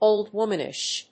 音節óld‐wóm・an・ish 発音記号・読み方
/‐nɪʃ(米国英語)/